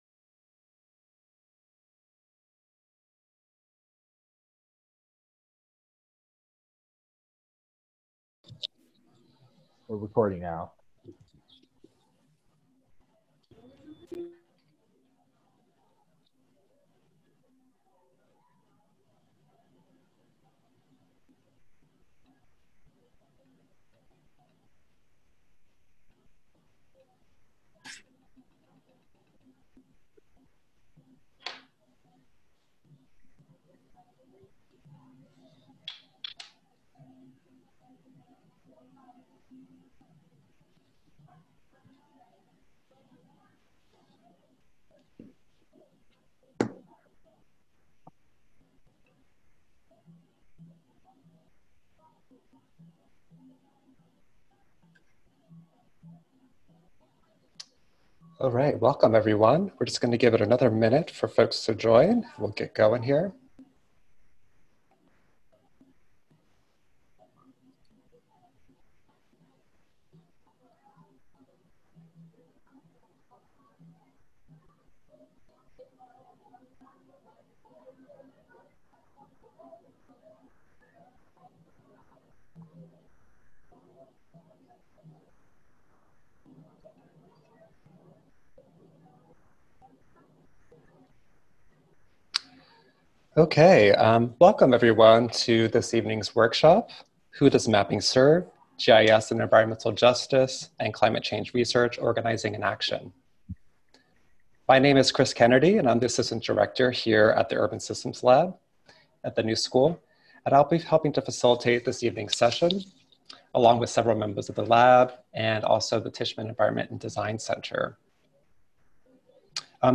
Audio recording of event Who Does Mapping Serve?
GIS in Environmental Justice and Climate Change Research. This workshop brings together members of the Urban Systems Lab, a design and practice at the New School, to share insights into past and ongoing work, and to help contextualize issues related to GIS and urban ecology through a presentation of case studies, breakout sessions and collaborative discussion.